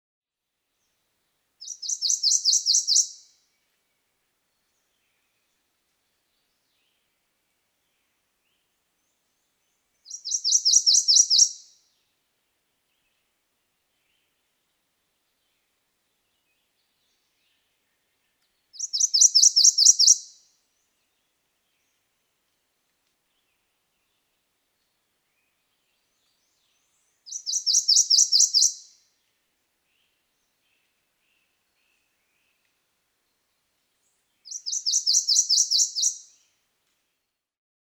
Prothonotary Warbler | Hunterdon Art Museum